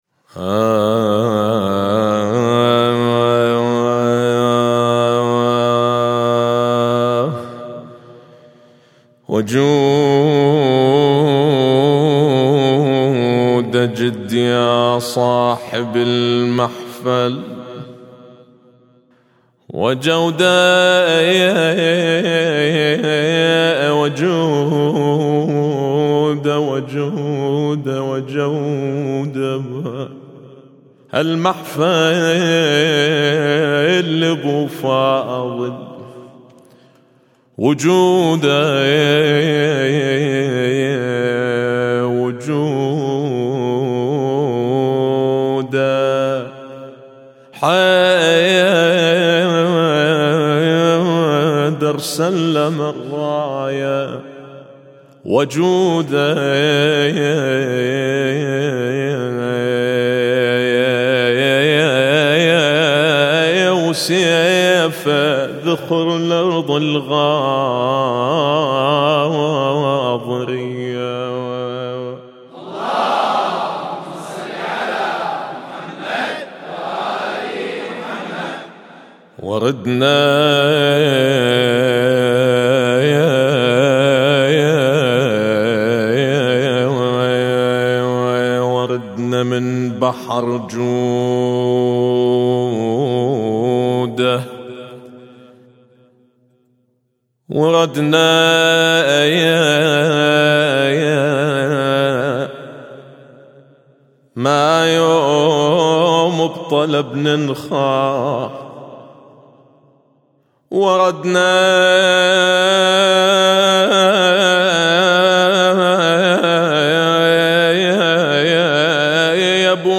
أبوذيات بمدح أبي الفضل العباس عليه السلام